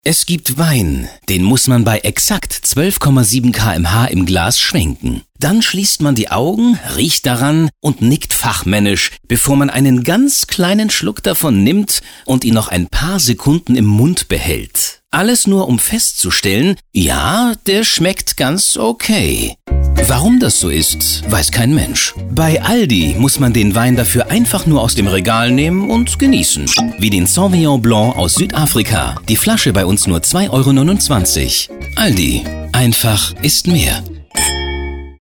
4_aldi_funkspot_wein.mp3